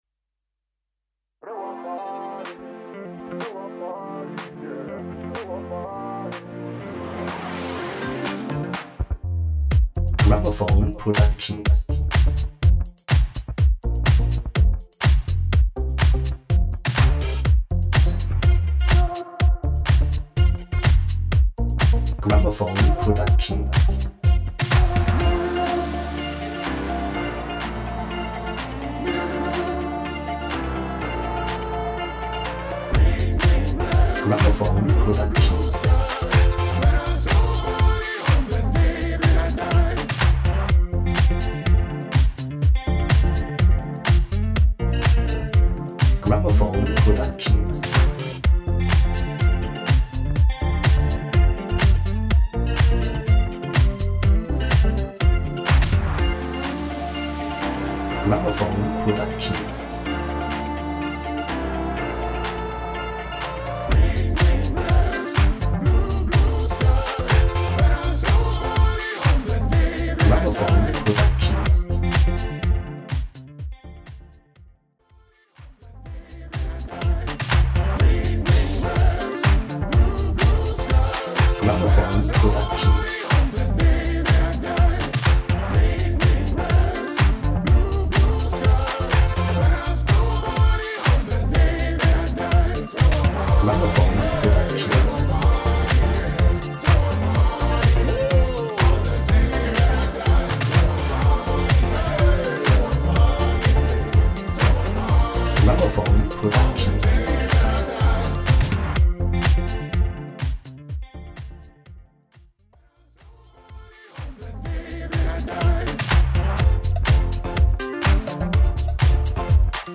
Singing Call
INSTRUMENTAL (ohne Lead Instrumente)